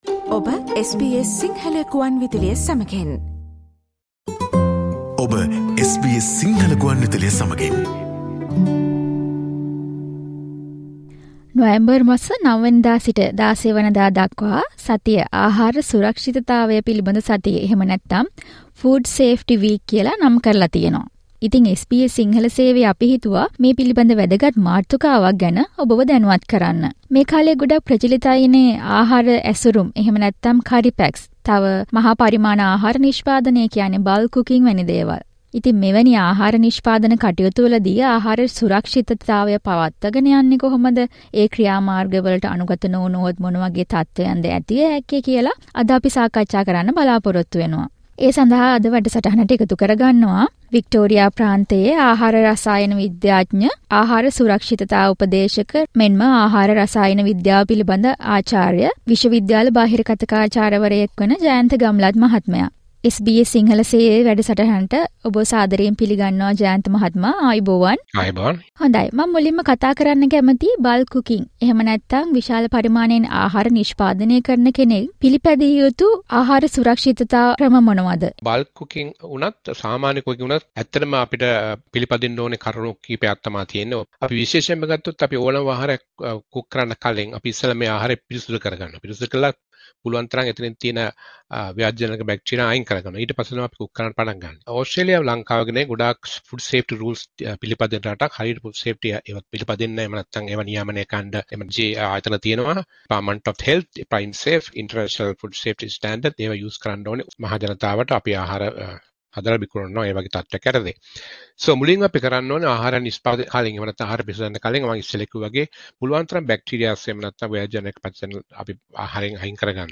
Listen to SBS Sinhala discussion about food safety procedures during bulk food cooking and making curry packs.